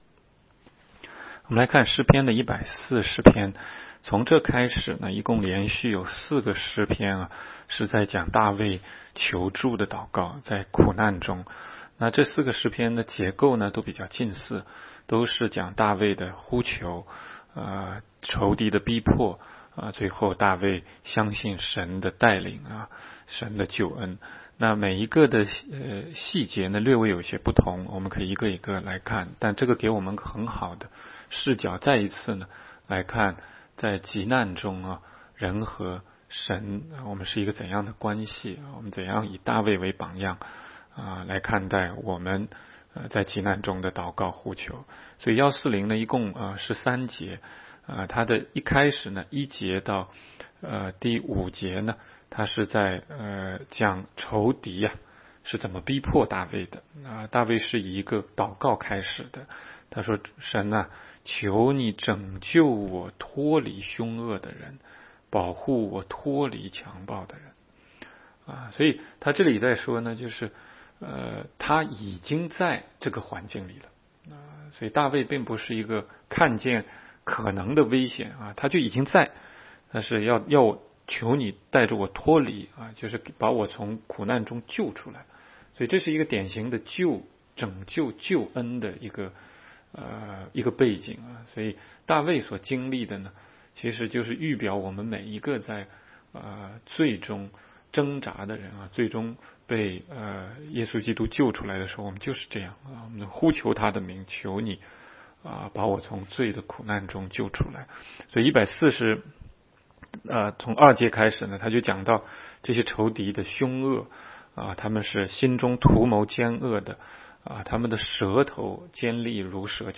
16街讲道录音 - 每日读经 -《 诗篇》140章